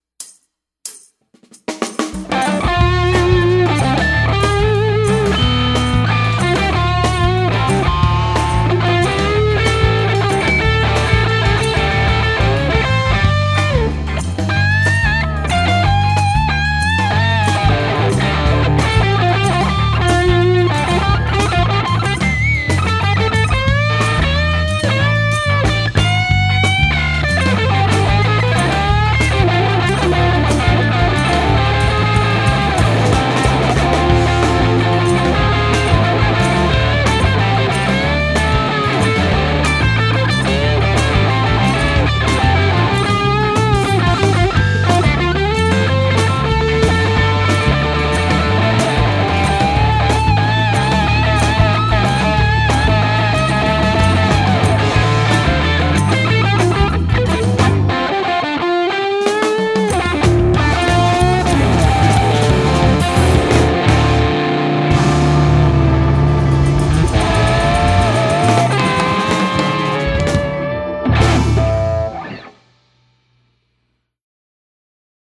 Taustassa on etelään ajamisen tunnelmaa:
Kiihkeää ja räväkkää.
Rajua rypistystä.
ankaraa rouhintaa "antaa mennä" mentaliteetilla säröisellä soundilla